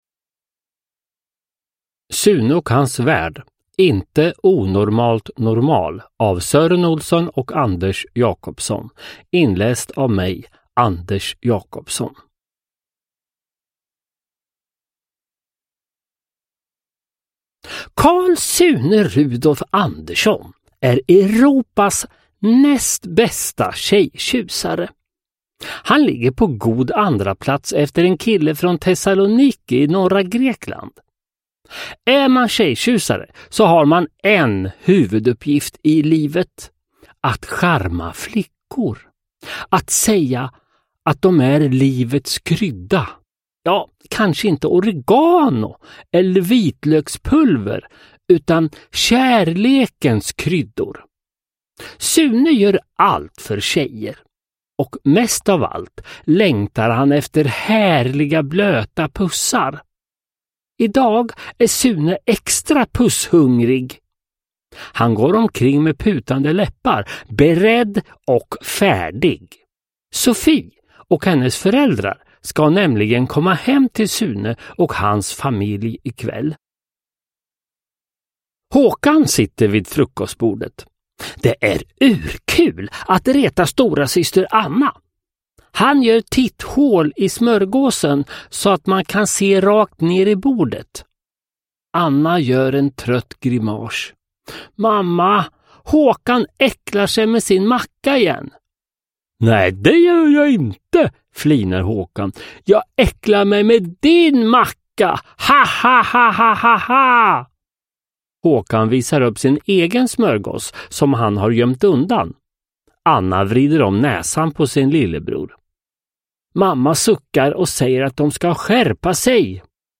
Inte onormalt normal – Ljudbok – Laddas ner
Uppläsare: Anders Jacobsson